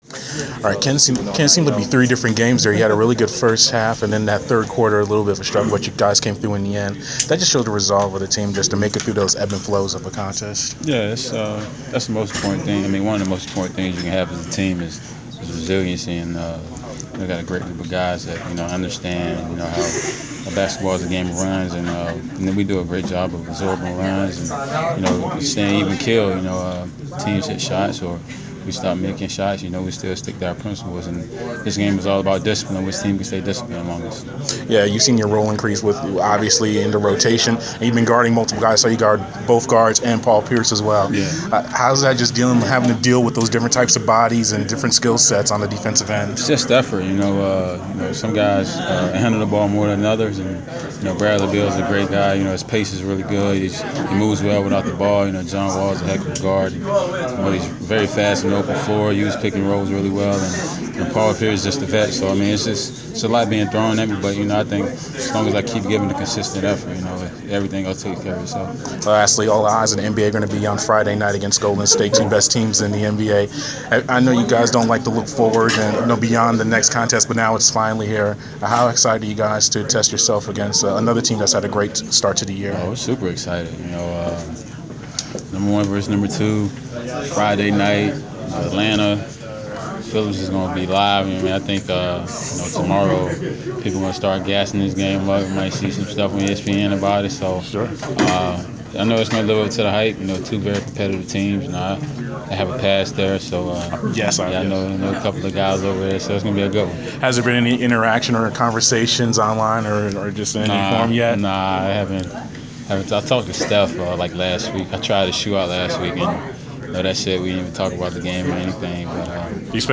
Inside the Inquirer: Postgame interview with Atlanta Hawks’ Kent Bazemore (2/4/15)
We caught up with Atlanta Hawks’ guard Kent Bazemore following his team’s 105-96 home victory over the Washington Wizards on Feb. 4.